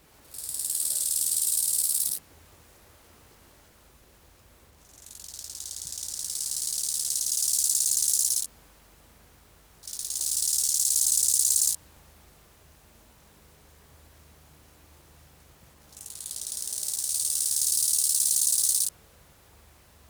Chorthippus_biguttulus.wav